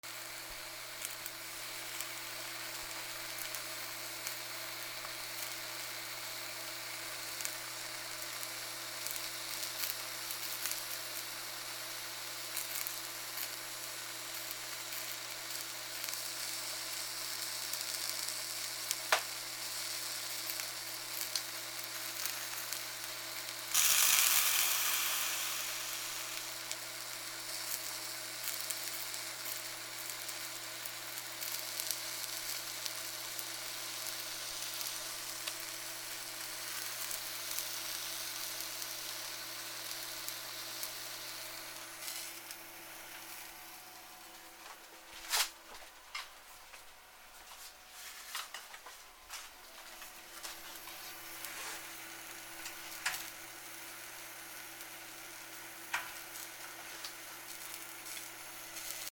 なすびを焼く 換気扇の雑音あり